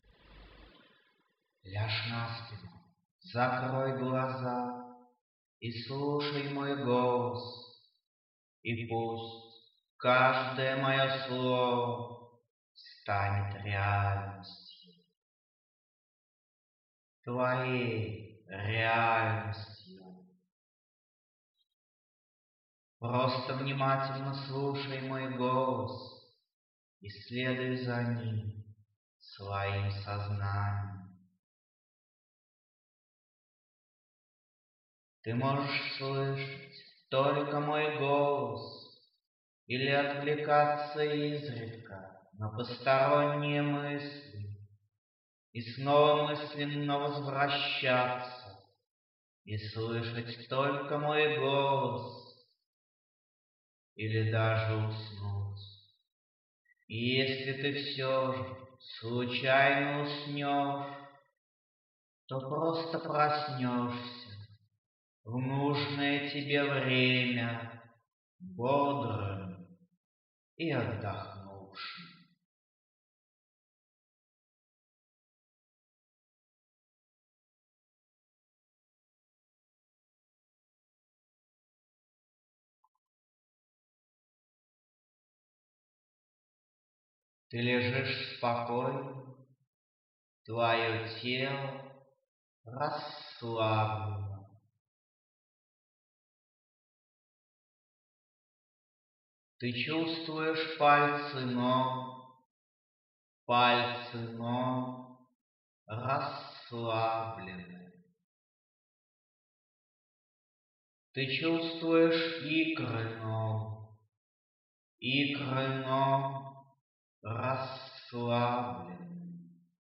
Музыка для медитации